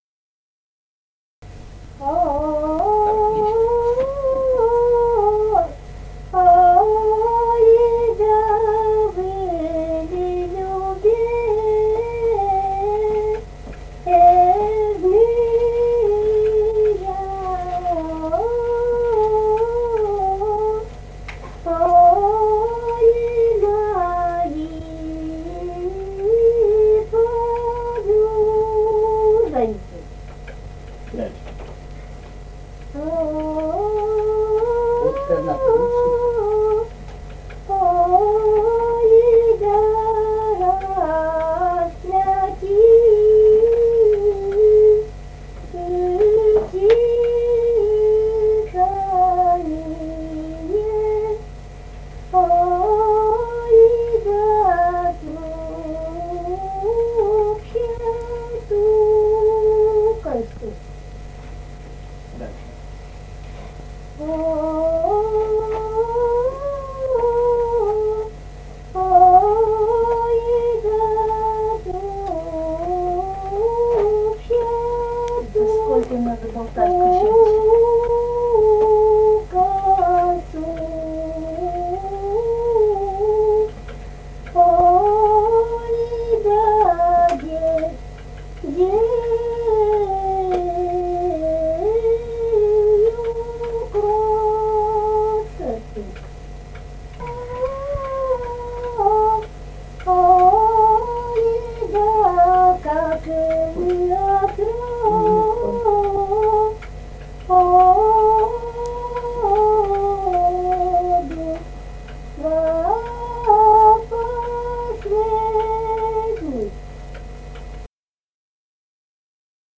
Республика Казахстан, Восточно-Казахстанская обл., Катон-Карагайский р-н, с. Фыкалка, июль 1978.